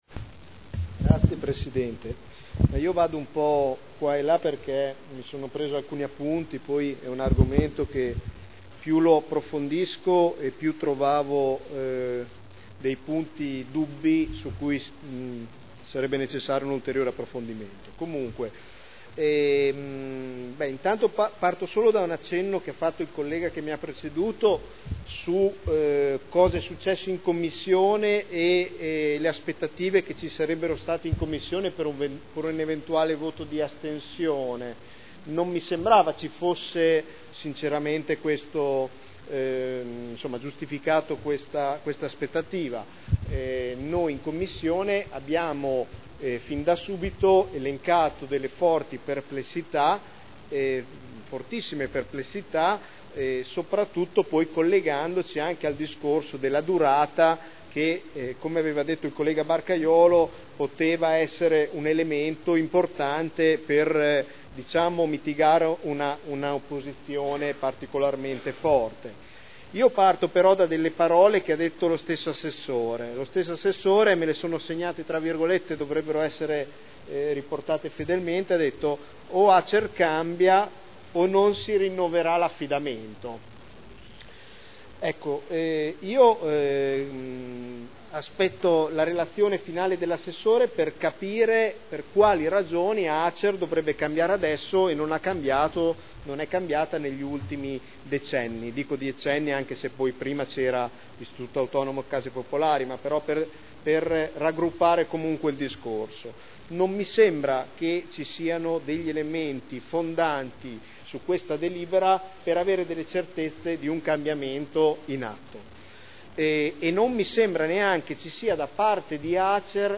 Nicola Rossi — Sito Audio Consiglio Comunale
Seduta del 20/12/2010. Dibattito su delibera: Affidamento in concessione all’Azienda Casa Emilia Romagna del servizio di gestione del patrimonio di Edilizia Residenziale pubblica di proprietà del Comune – Approvazione Accordo Quadro provinciale e Contratto di Servizio del Comune di Modena (Commissione consiliare del 7 dicembre 2010)